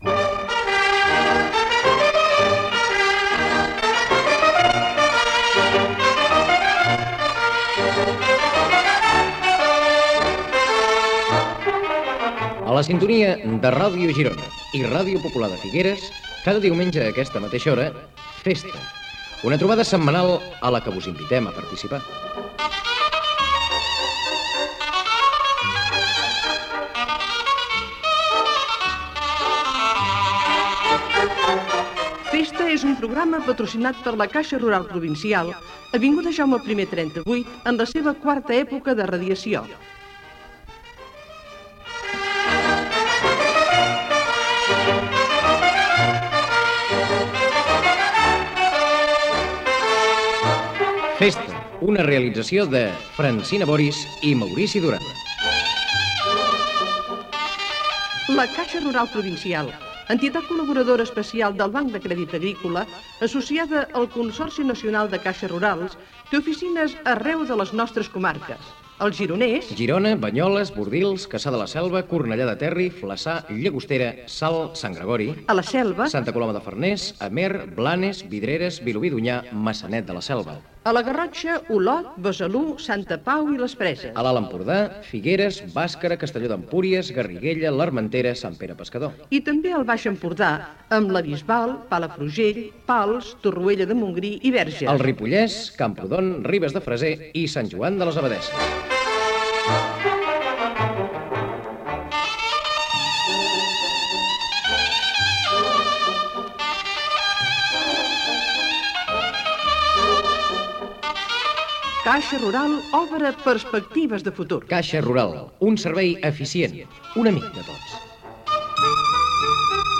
Careta del programa, publicitat,resum de la campanya "Salvem la natura" i la campanya de prevenció d'incendis, el mes de juny i una sardana de Juli Garreta
Entreteniment